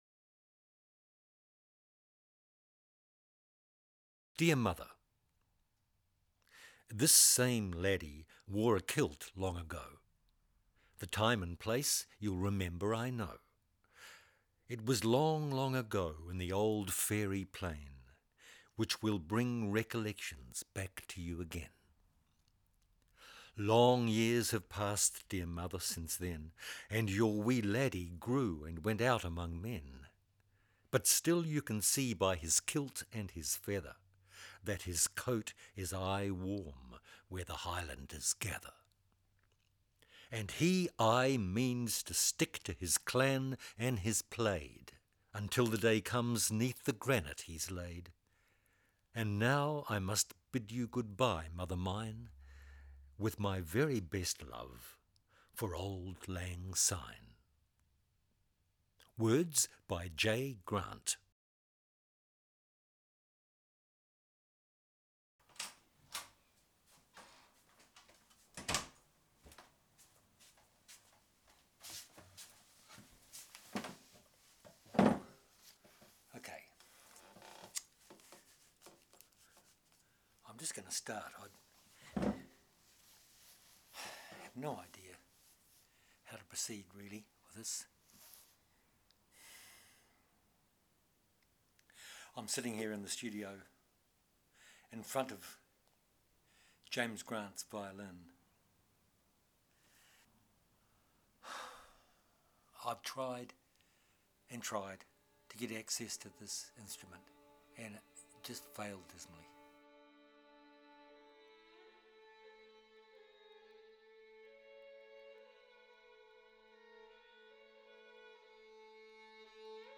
NOTE: When experienced in the acousmonium of STUDIO174, the violin is installed centre stage in a cone of light.